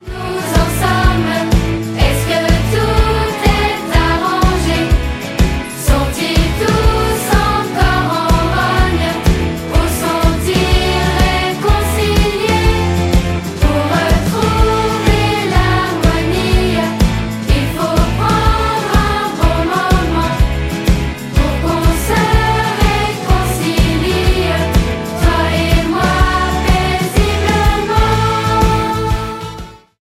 Album musical